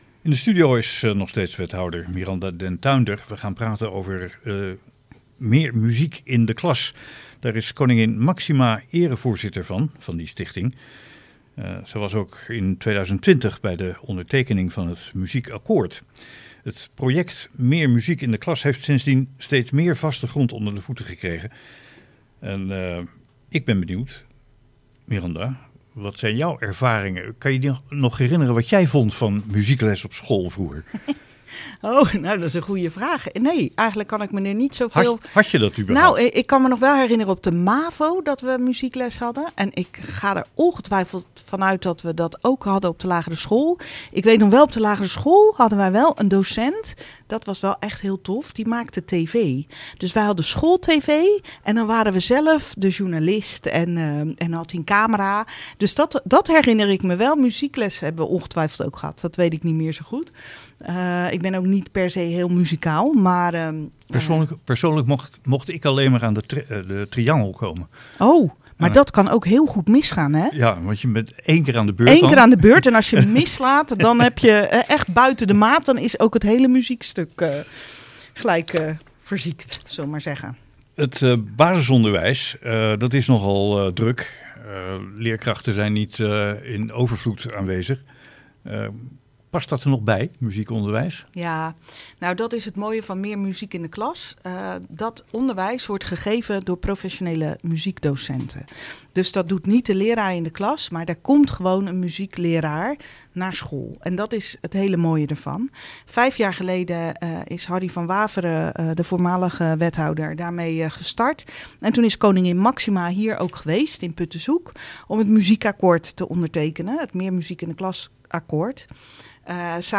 In het programma Hoeksche Waard Actueel vertelde wethouder Miranda den Tuinder over dit mooie project.